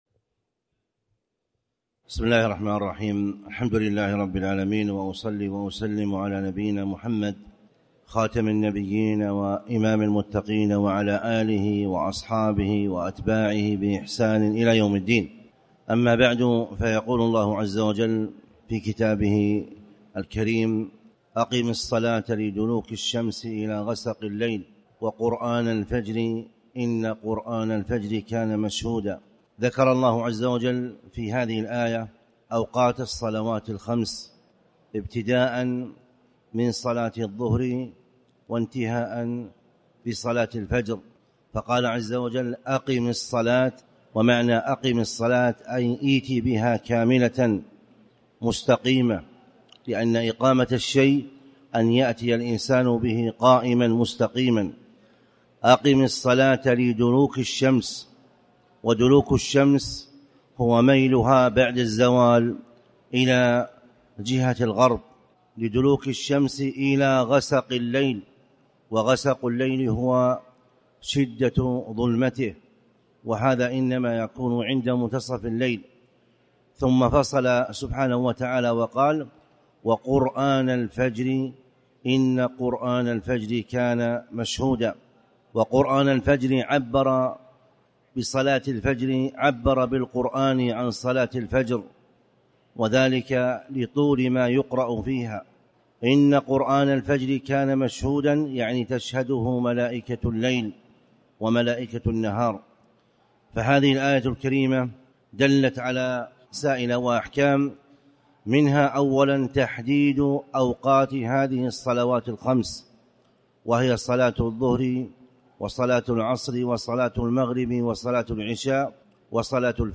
تاريخ النشر ١٥ صفر ١٤٣٨ هـ المكان: المسجد الحرام الشيخ